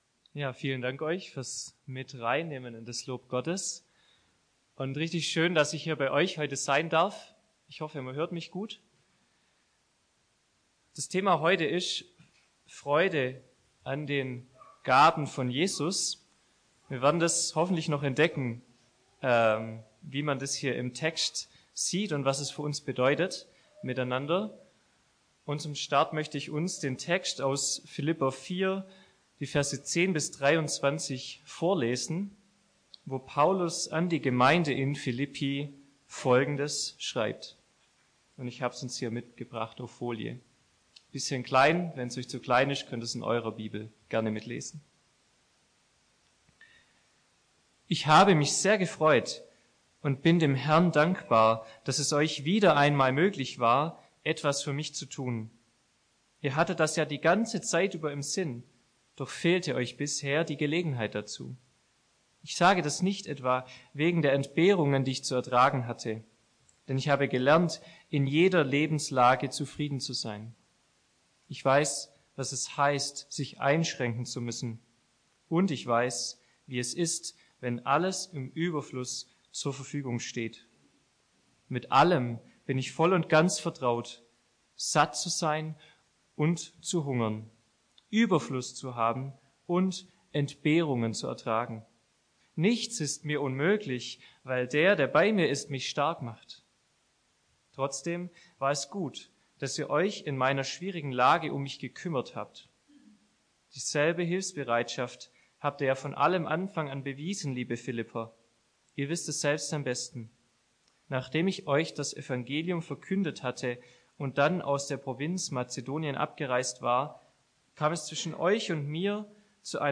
Predigten aus der Fuggi